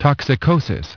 Транскрипция и произношение слова "toxicosis" в британском и американском вариантах.